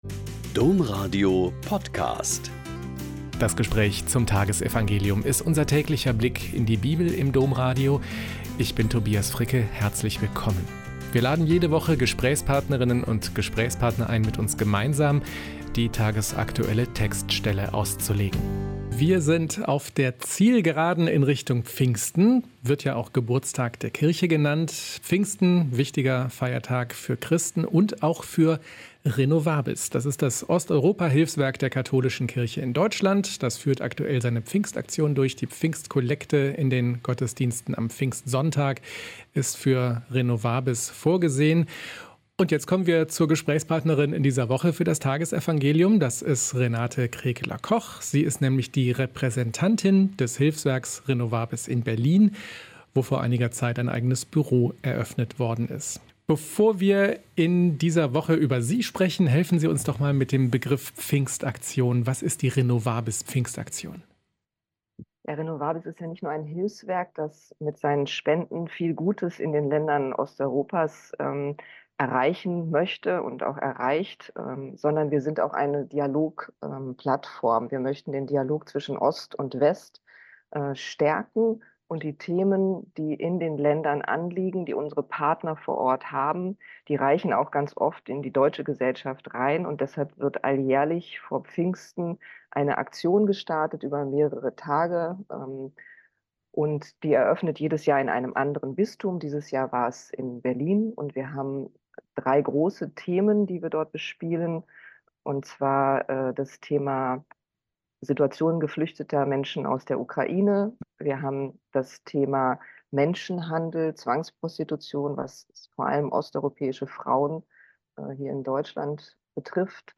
Joh 16,29-33 - Gespräch